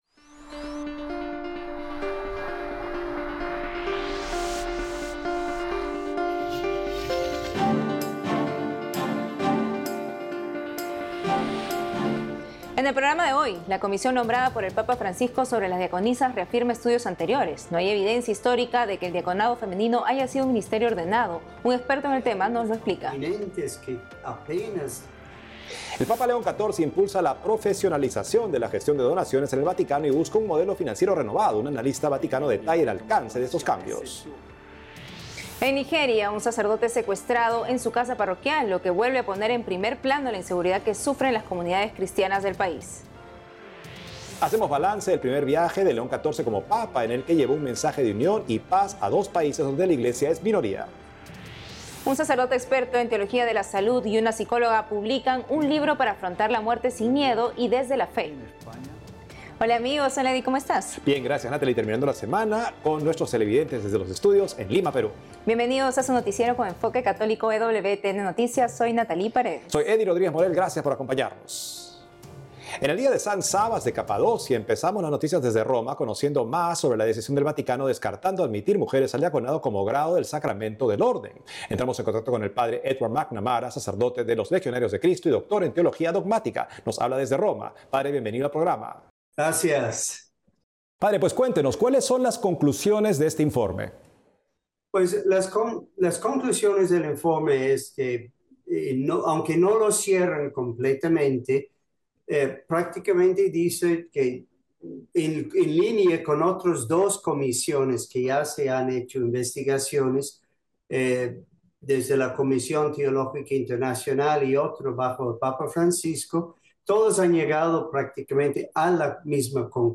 Noticiero diario producido exclusivamente para EWTN por la agencia ACI Prensa de Perú. Este programa informativo de media hora de duración se emite los sábados (con repeticiones durante la semana) y aborda noticias católicas del mundo y las actividades de Su Santidad Francisco; incluye también reportajes a destacados católicos de América del Sur y América Central.